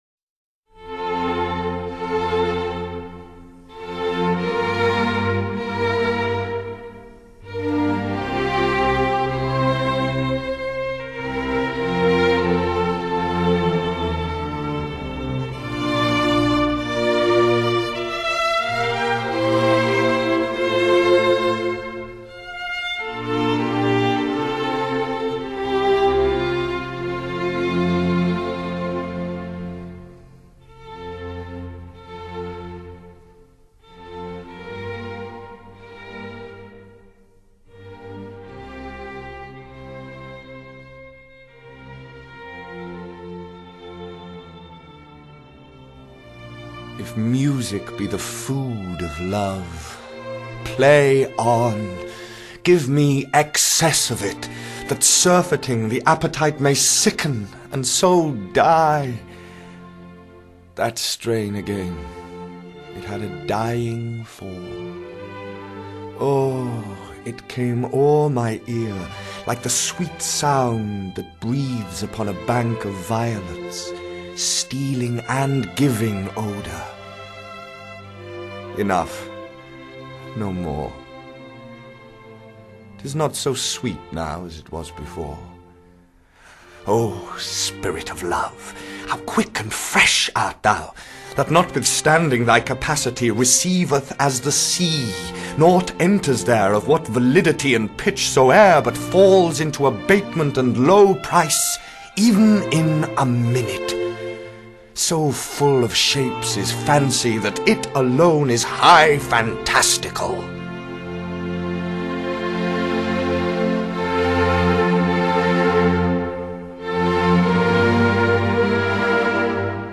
专辑类别：爵士/古典/配乐
『假如音乐是爱情的食粮，那么奏下去吧...』名著与古典乐完美结合